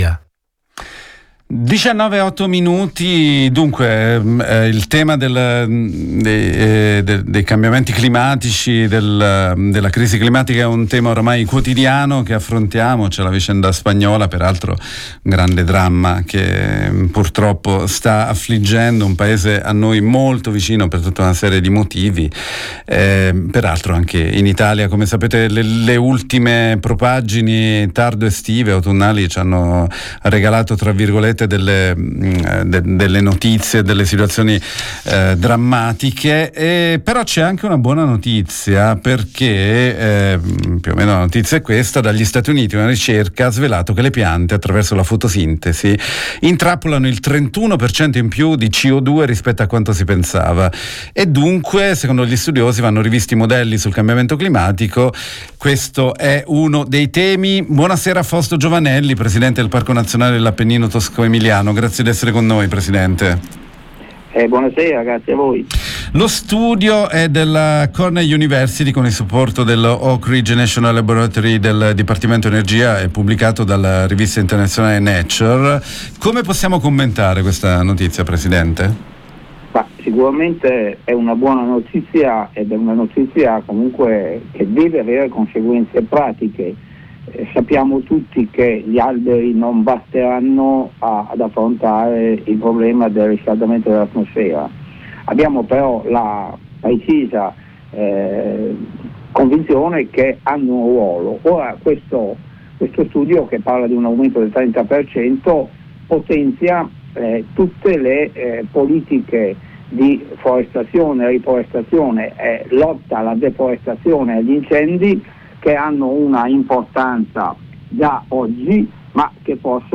Intervista con FAUSTO GIOVANELLI, presidente del Parco Nazionale dell’Appennino tosco emiliano